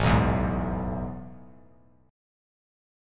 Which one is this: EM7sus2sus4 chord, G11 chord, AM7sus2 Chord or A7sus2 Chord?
AM7sus2 Chord